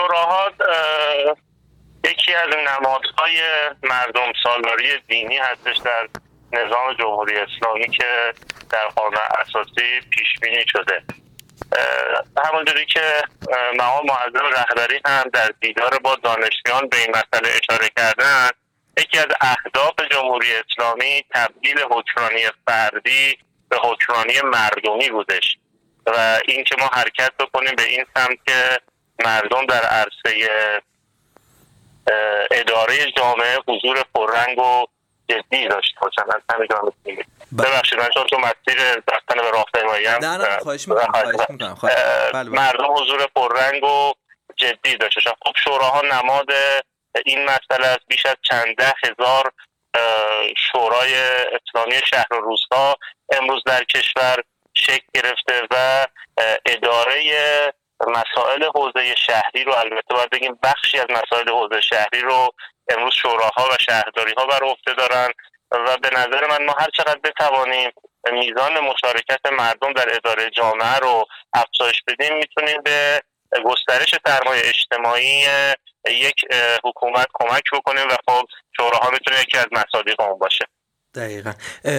مهدی اقراریان در گفت‌وگو با ایکنا تبیین کرد؛